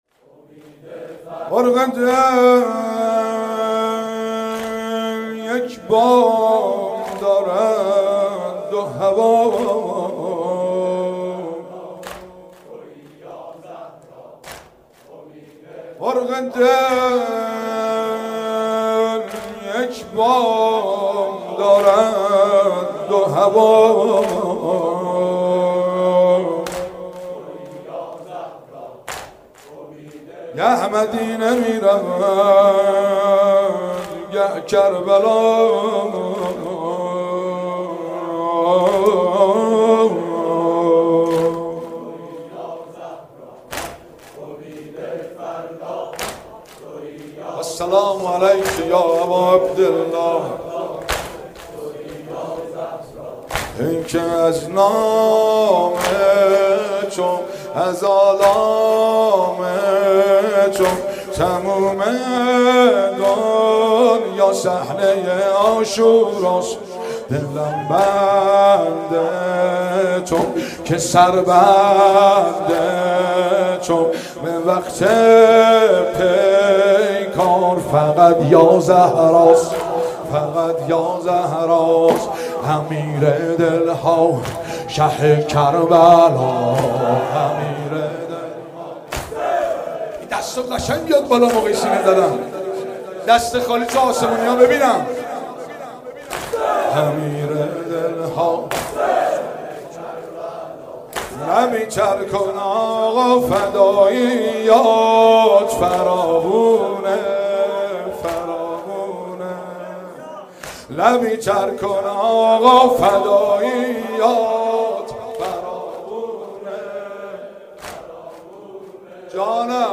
روضه محمود کریمی